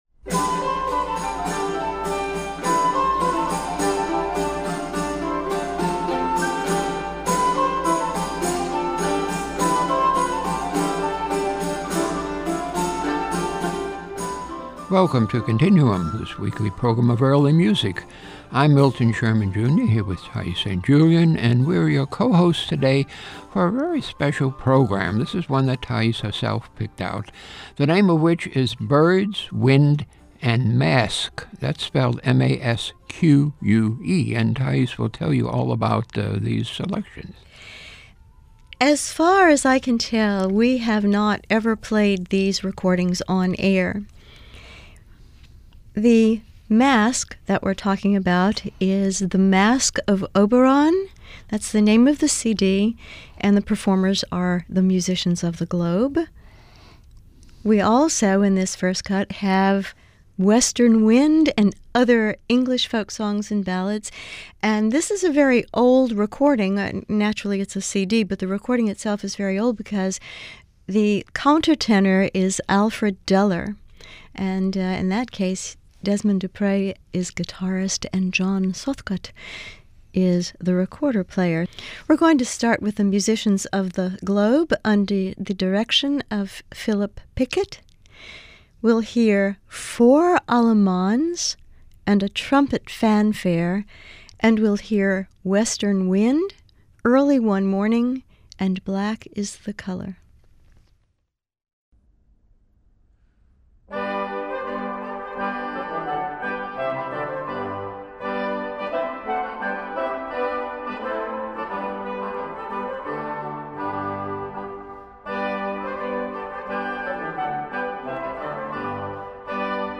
early music
Performances are by three ensembles of great note.